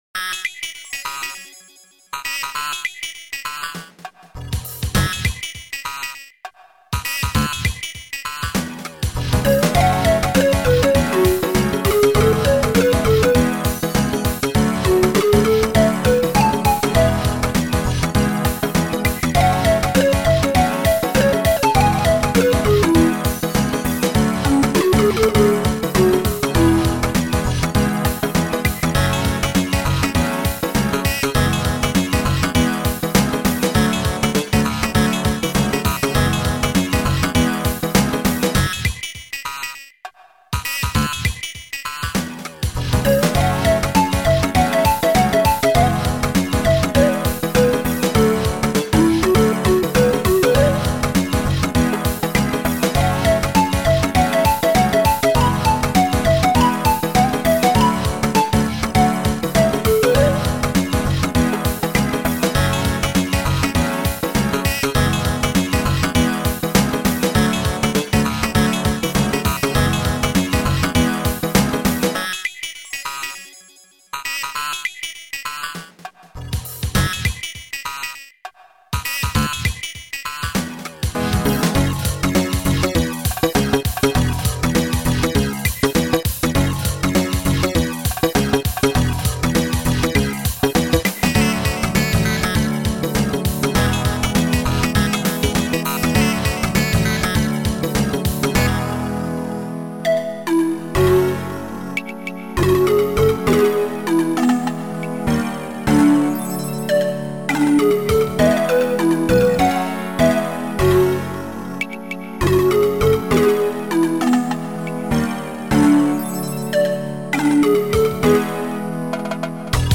Sound Format: Noisetracker/Protracker
Sound Style: Groovy